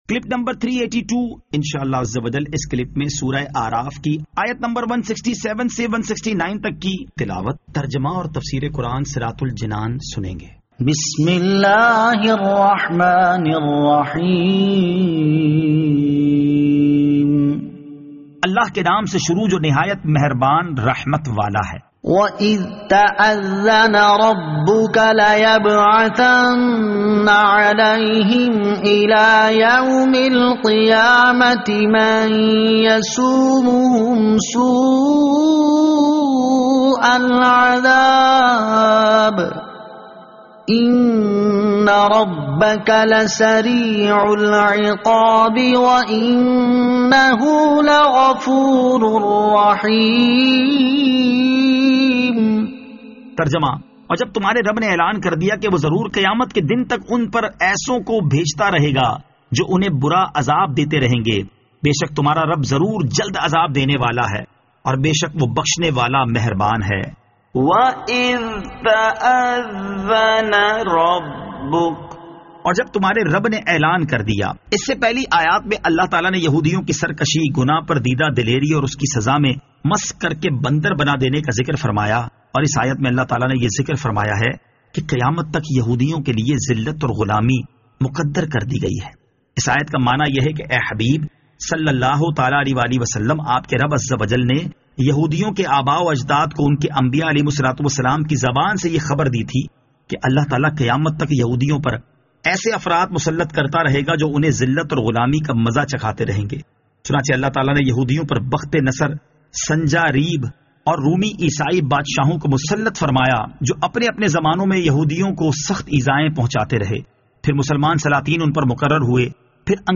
Surah Al-A'raf Ayat 167 To 169 Tilawat , Tarjama , Tafseer